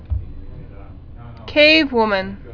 (kāvwmən)